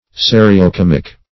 Serio-comic \Se`ri*o-com"ic\, Serio-comical \Se`ri*o-com"ic*al\,